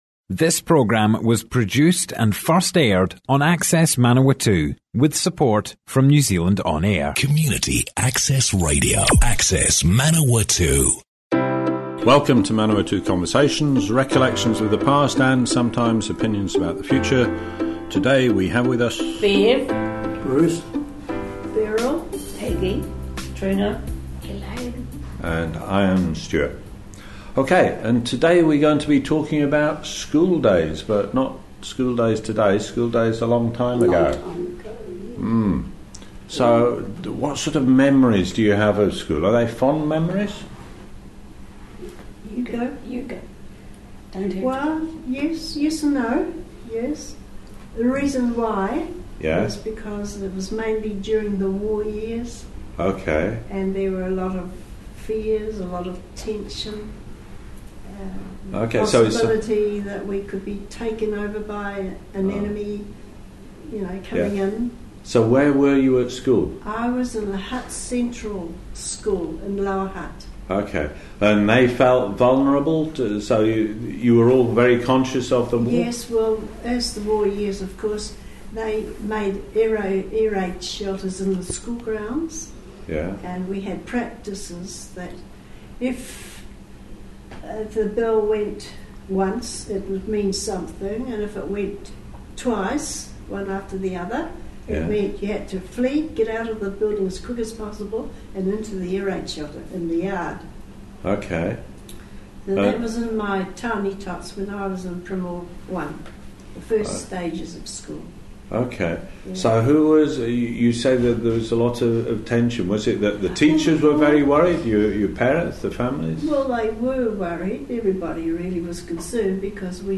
00:00 of 00:00 Add to a set Other Sets Description Comments Abbeyfield residents, school days in World War Two - Manawatu Conversations More Info → Description Broadcast on Access manawatu, 12 June 2018.
oral history